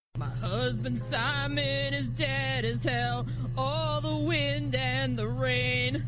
teenwindandrain.mp3